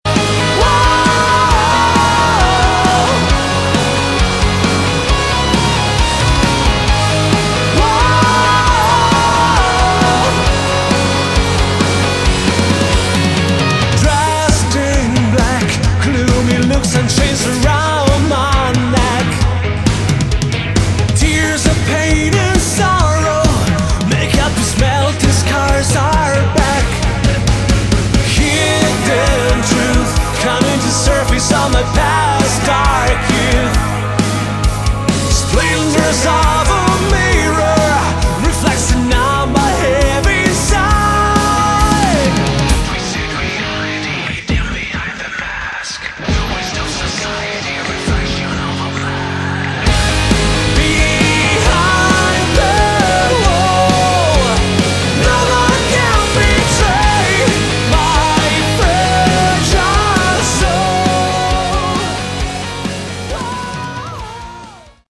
Category: Melodic/Symphonic Metal
guitars, keyboards and orchestral arrangements
lead and backing vocals